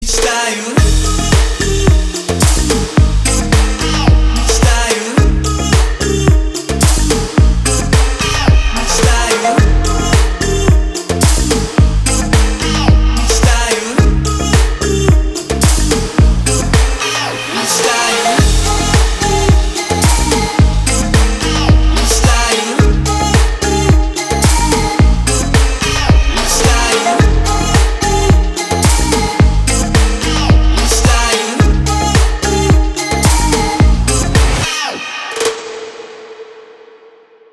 • Качество: 320, Stereo
поп
мужской вокал
dance
романтичные